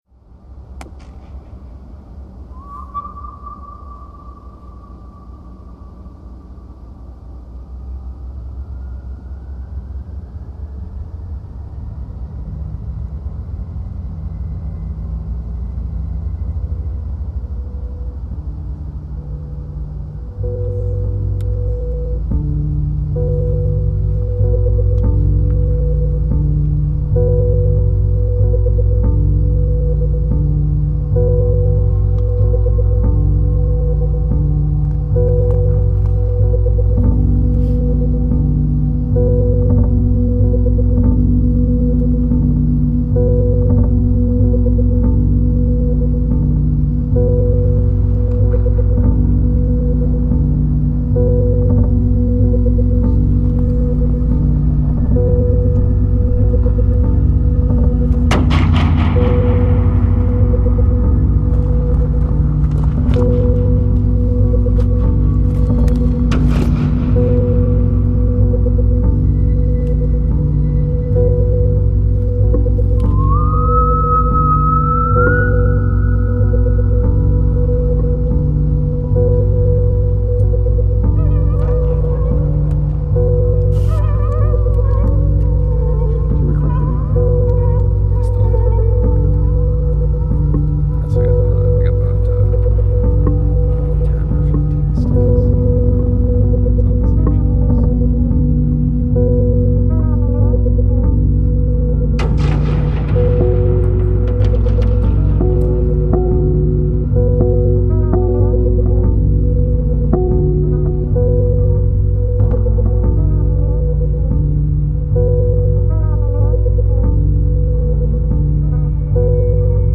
whistles